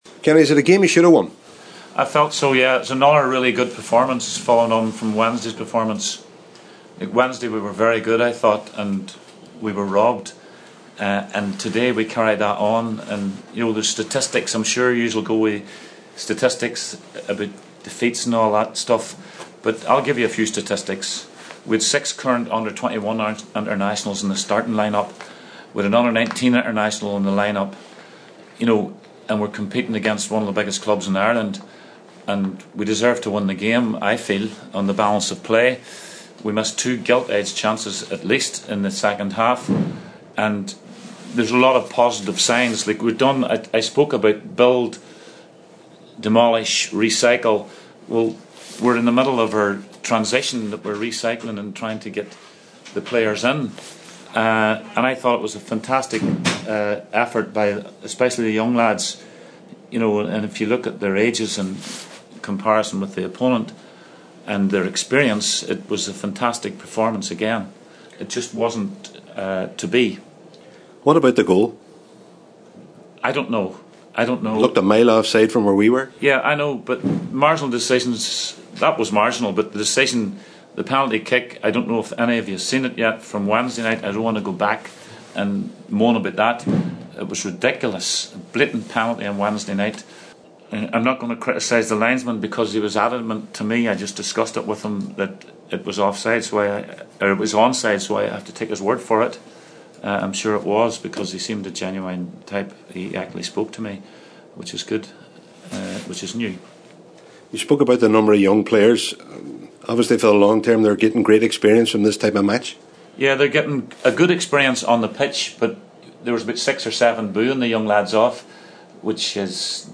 Derry manager Kenny Shiels gave his thoughts